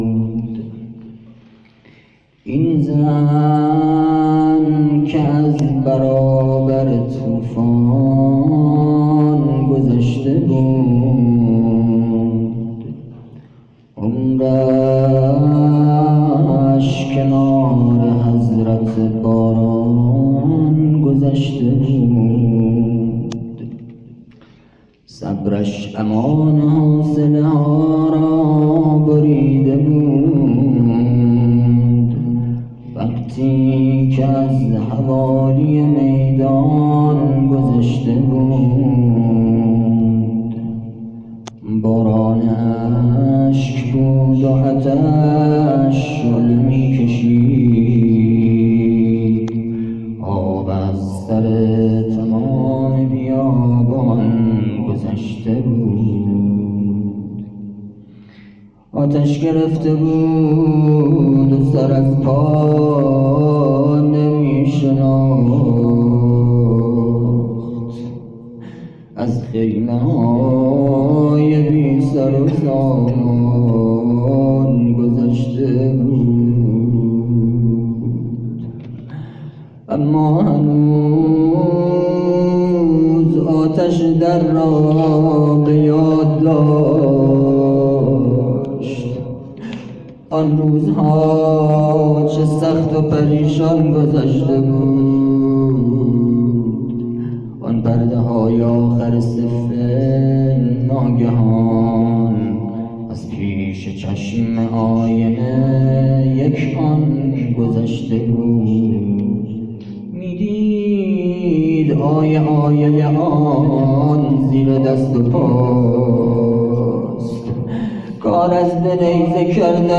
شعر پایانی